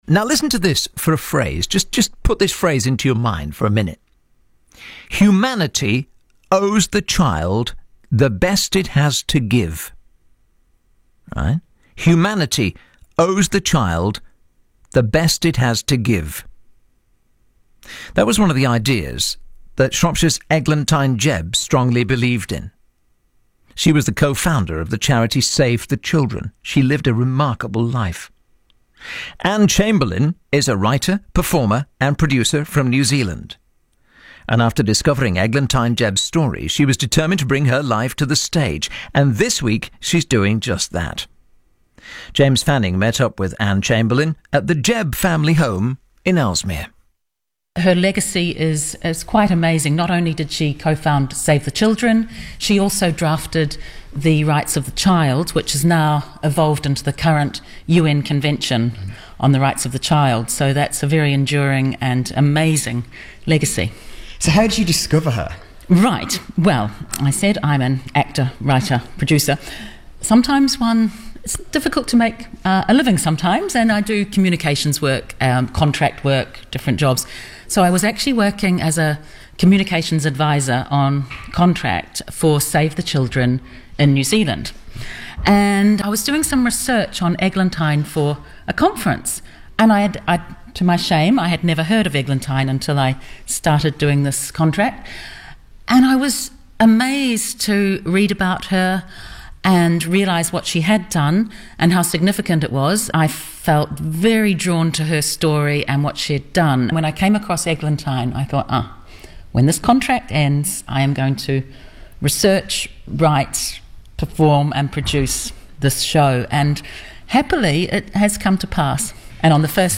RADIO INTERVIEWS
EGLANTYNE BBC interview1.mp3